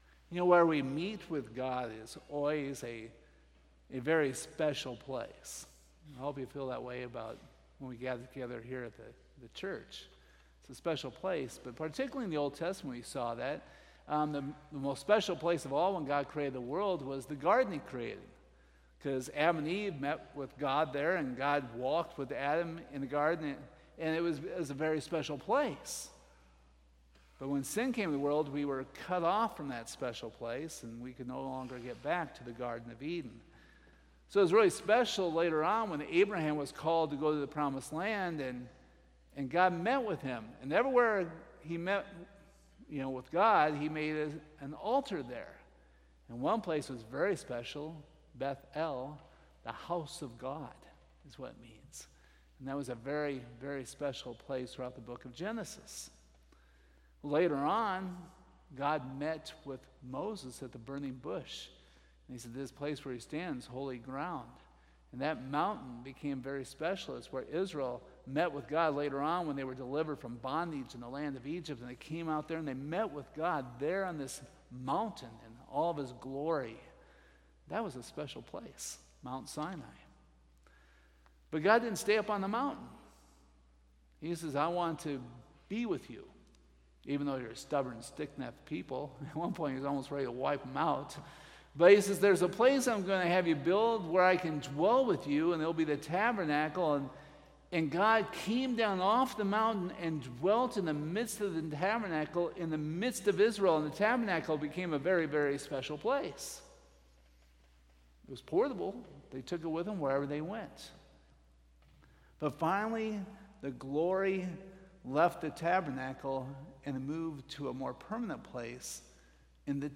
Hebrews 8:1-6 Service Type: Sunday Morning Topics